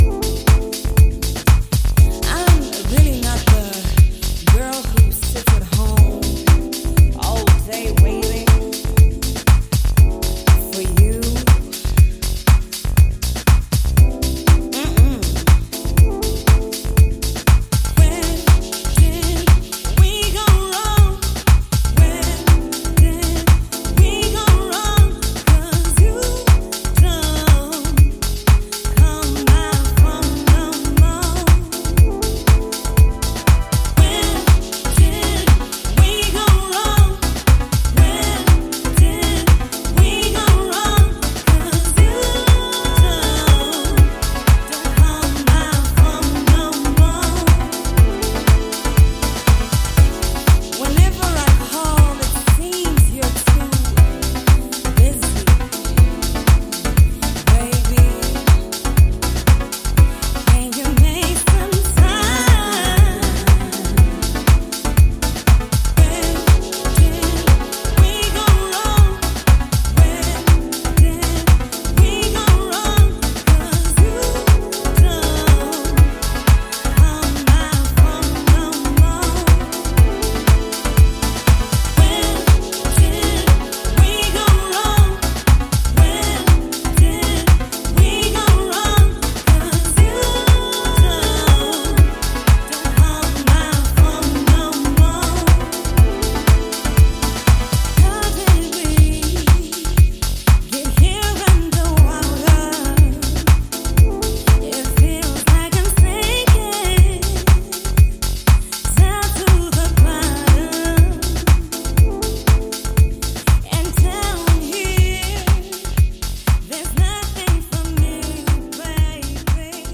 Both are 2 incredibly deep messages to HOUSE MUSIC.